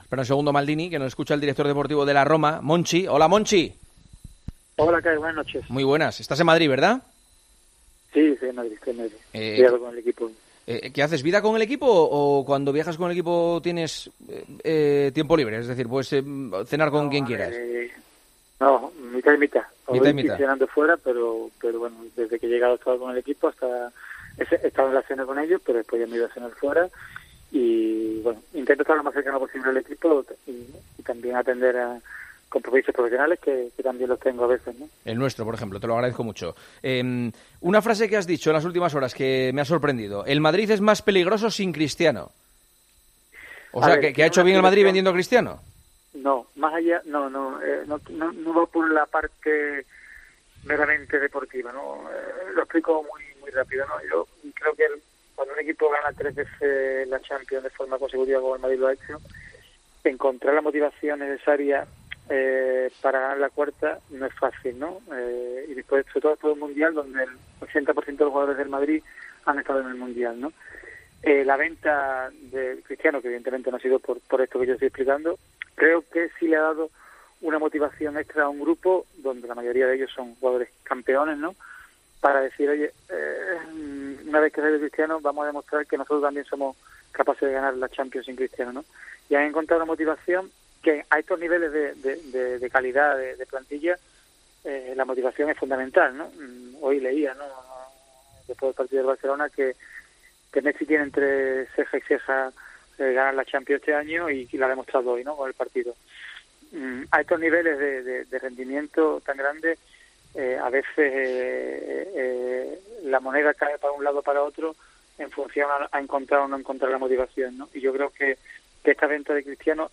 El director deportivo de la Roma habla en COPE: "No valoro cosas que no tienen sentido, como una oferta del Barça"